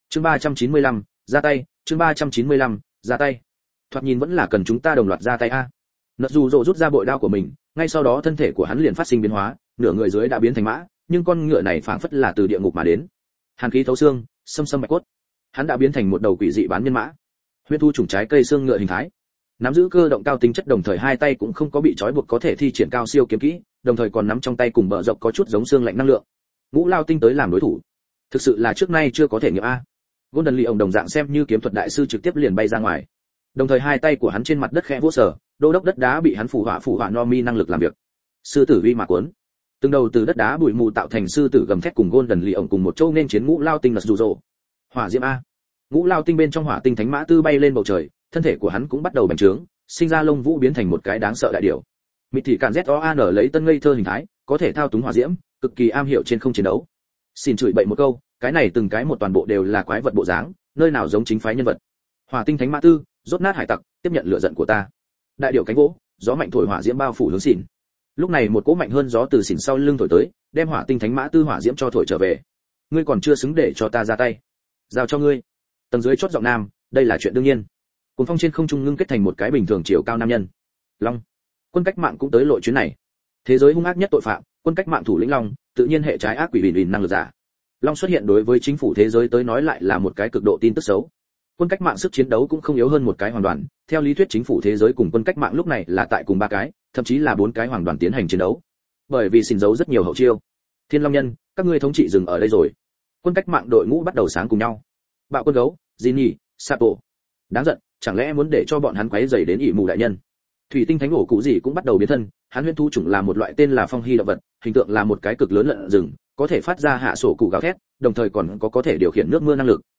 Hải Tặc: Đại Hành Trình Cùng Meowth Audio - Nghe đọc Truyện Audio Online Hay Trên RADIO TRUYỆN FULL